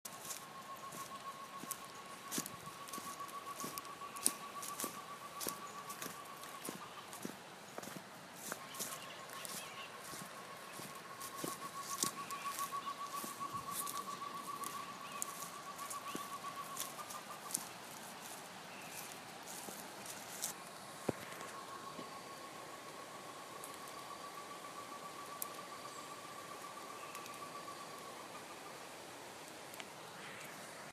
Short-tailed Antthrush (Chamaeza campanisona)
Life Stage: Adult
Location or protected area: Campo Ramón
Condition: Wild
Certainty: Recorded vocal
Canto-4.mp3